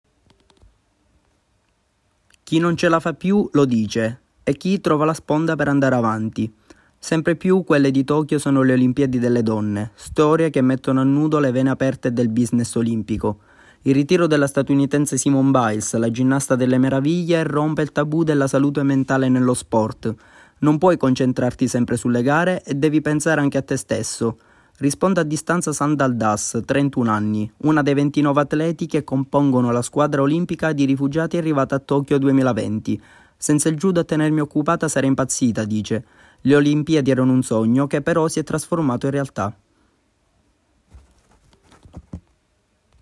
Go isla go. Seimila km in bici per comprare tre anni di cibo per una casa famiglia nel sud delle Filippine. Il servizio